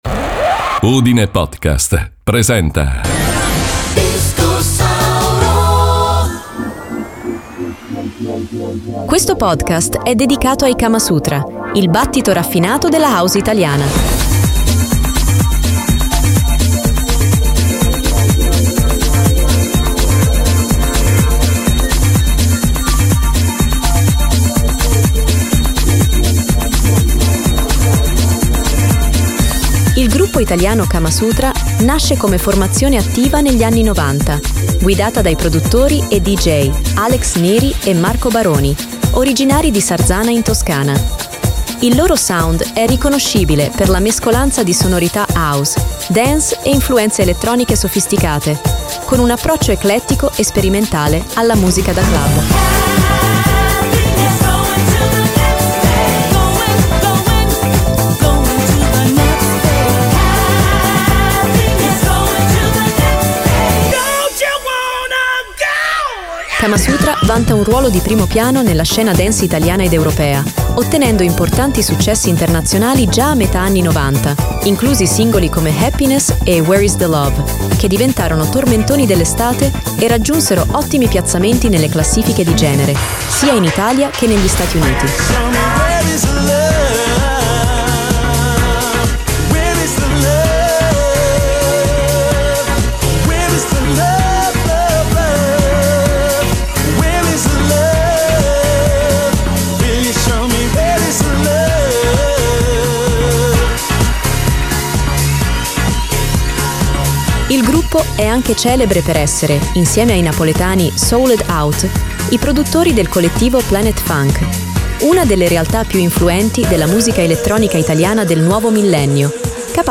La musica di Kamasutra si distingue per il connubio tra groove e melodia, inserendo pattern ritmici coinvolgenti e un’eleganza sonora in cui si percepiscono tanto la ricerca stilistica quanto la pulsione verso la pista da ballo.